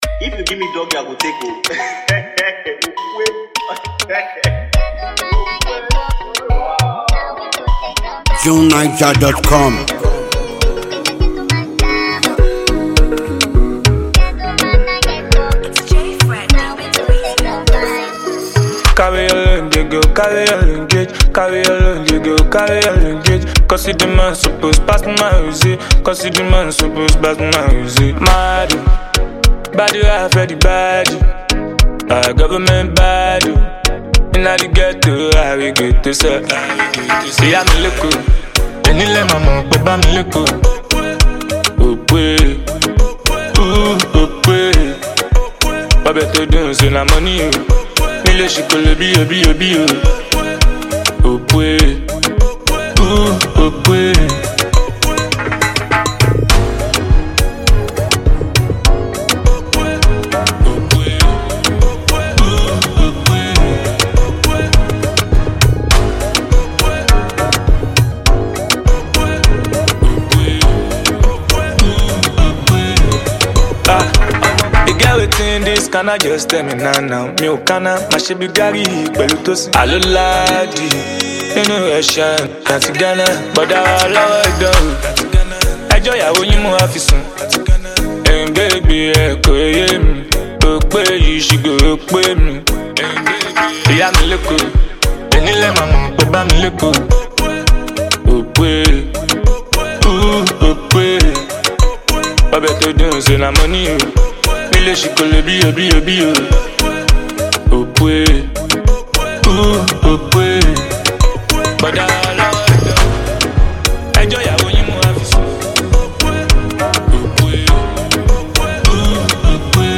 throbbing tune